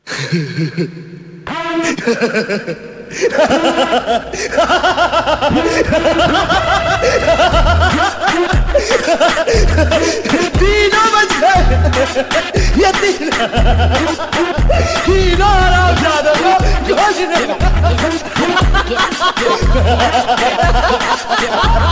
Category: Dailog